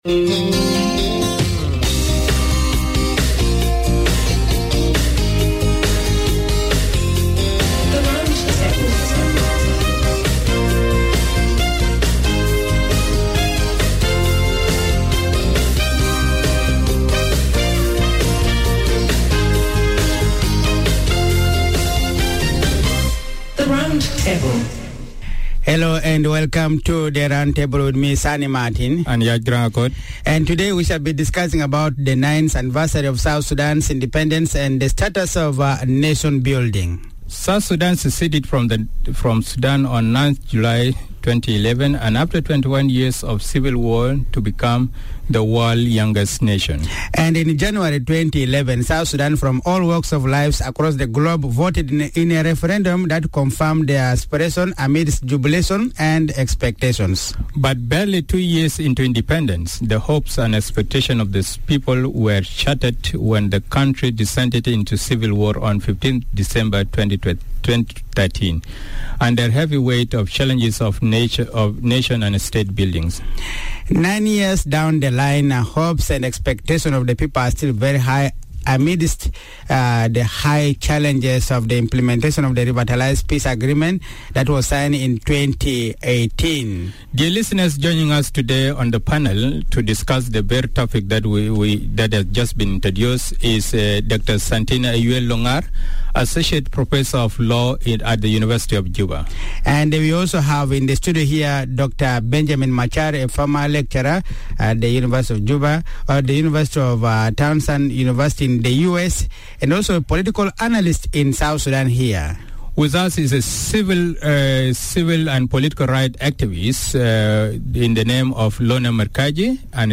hosted a group of experts